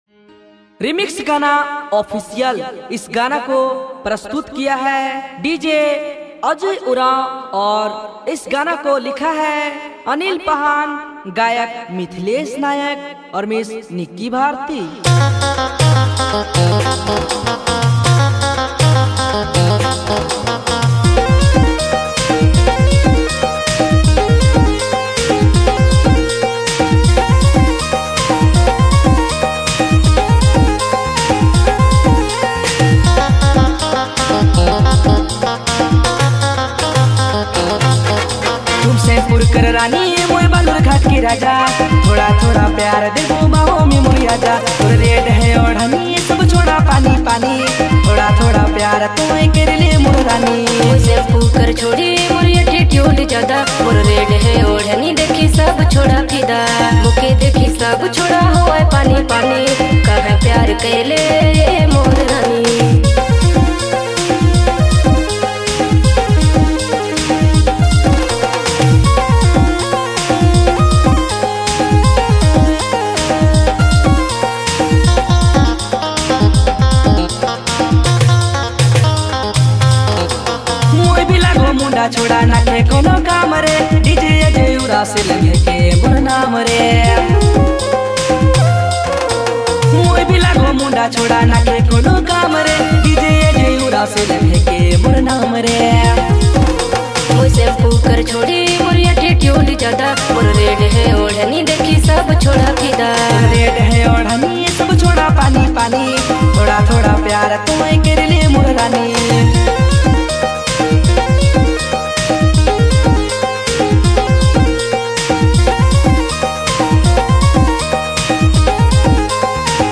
New Nagpuri All Singer Songs